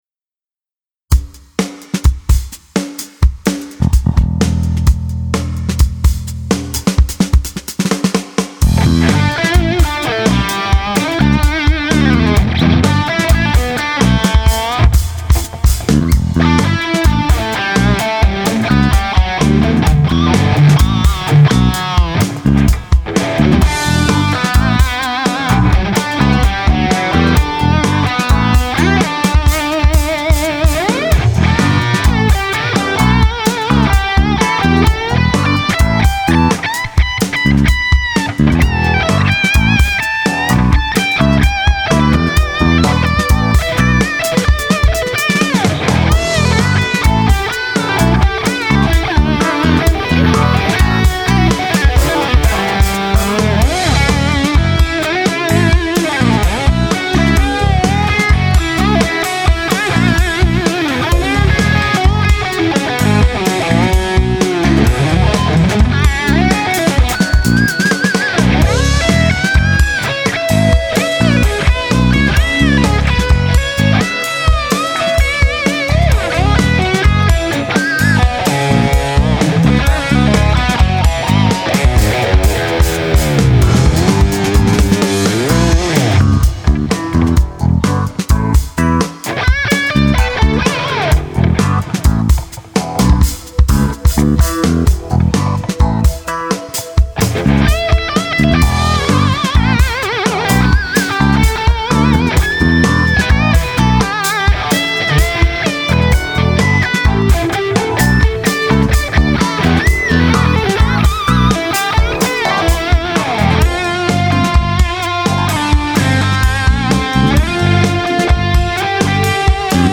Bin im Augenblick betriebsblind ;-) (die ersten paar Phrasen sind wieder so seltsam schräg, weiß ich :-) )
Aber in Wahrheit liegt der Klang an der echten und wahrhaften Fender Stratocaster American Standard mit nicht-wirklich-Humbucker-sondern-eher-SC-DiMarzio-HS3.
some hot playing here.
Nice legato and energy!
: Hier mein Beitrag zum 100sten  : Ist meine 2008er American Standard Strat, Axe Boogie 4-Simulation mit Firmware 9 und saftig Gain. Ein bisschen Cubase Echo-Spielerei, weil ich es halt nicht lassen kann.